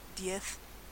Ääntäminen
Castilian: IPA: [d̠je̞θ] Latin America: IPA: [d̠je̞s]